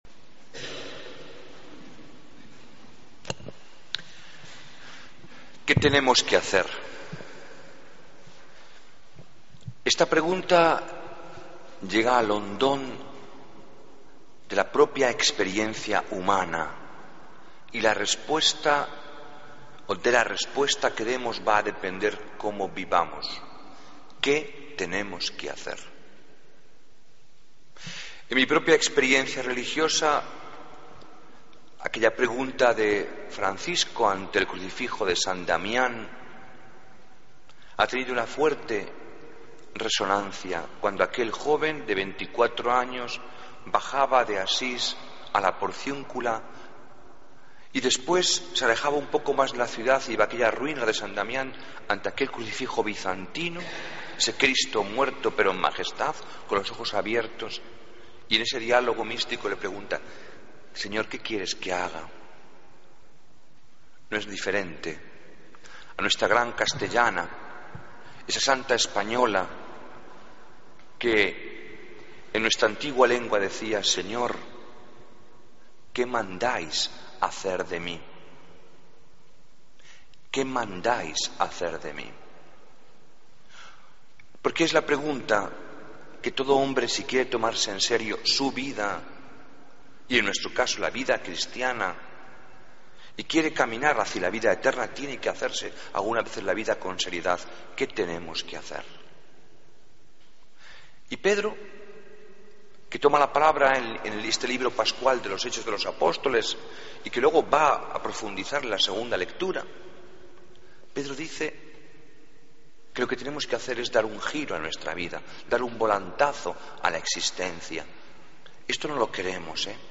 Homilía del Domingo 11 de Mayo de 2014